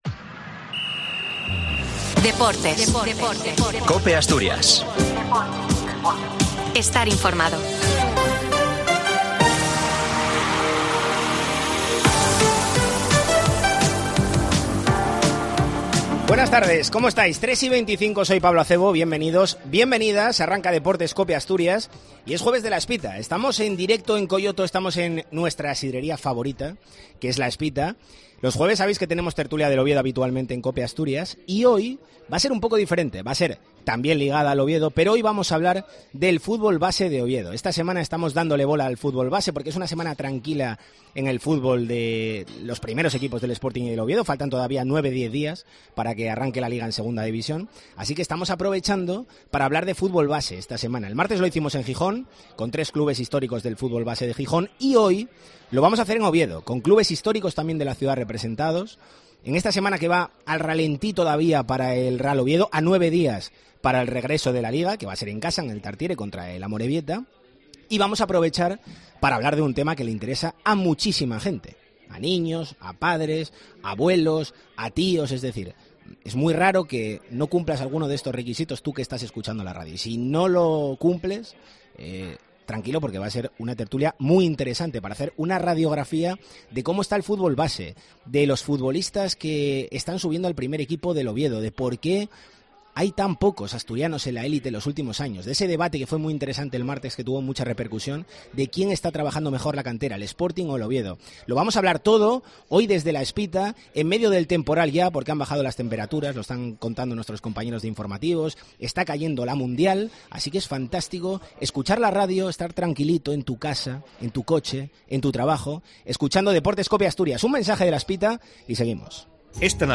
¿Trabaja mejor el Oviedo o el Sporting en cantera? Debatimos acerca del fútbol base en 'La Tertulia del fútbol base de Oviedo' en el capítulo de este jueves en Depprtes COPE Asturias , dese la sidrería La Espita .